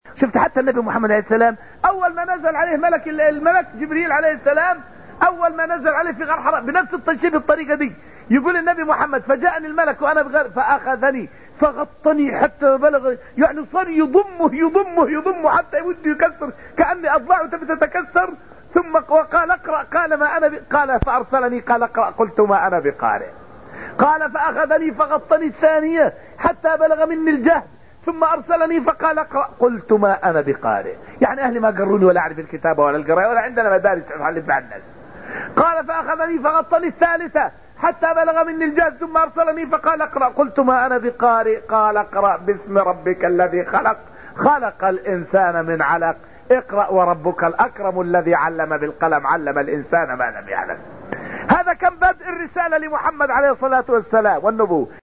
فائدة من الدرس الثامن من دروس تفسير سورة القمر والتي ألقيت في المسجد النبوي الشريف حول بدء الرسالة لمحمد صلى الله عليه وسلم.